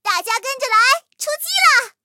M4谢尔曼出击语音.OGG